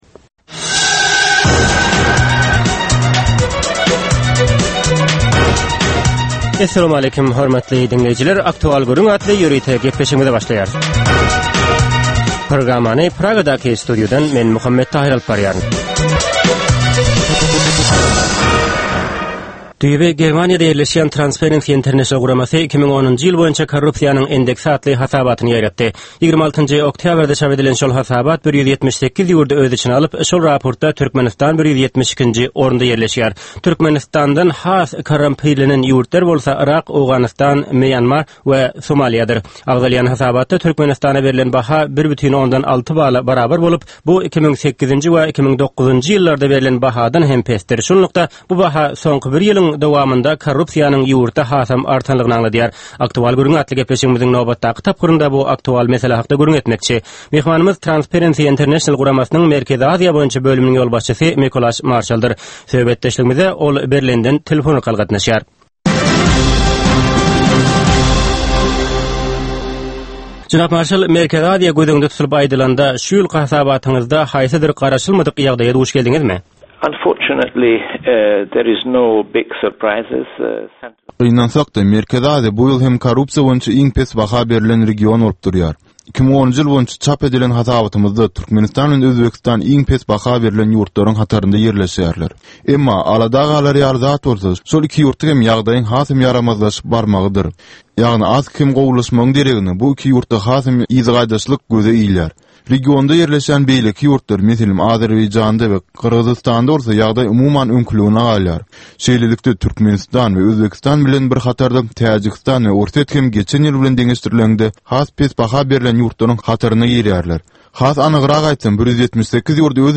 Türkmen halkynyň däp-dessurlary we olaryň dürli meseleleri barada ýörite gepleşik. Bu programmanyň dowamynda türkmen jemgyýetiniň şu günki meseleleri barada taýýarlanylan radio-dramalar hem efire berilýär.